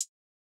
kits/OZ/Closed Hats/Hat 2.wav at main
Hat 2.wav